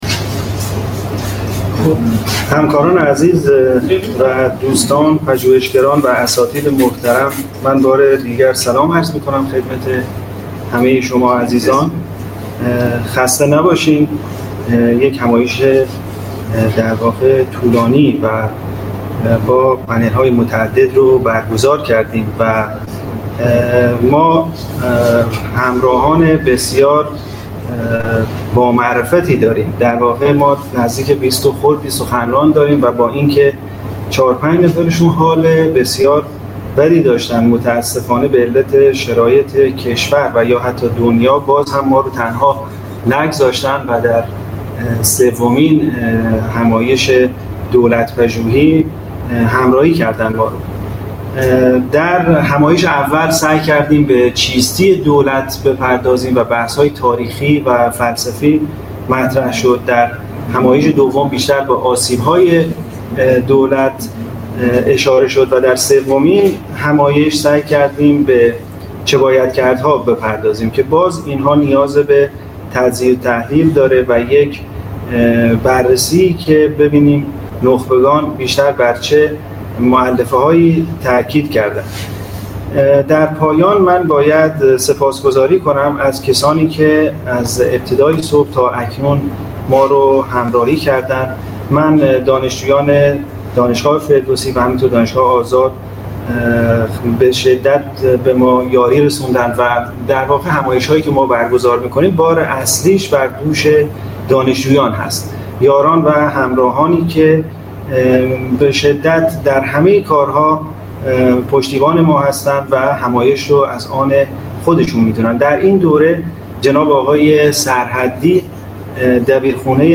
فایل سخنرانی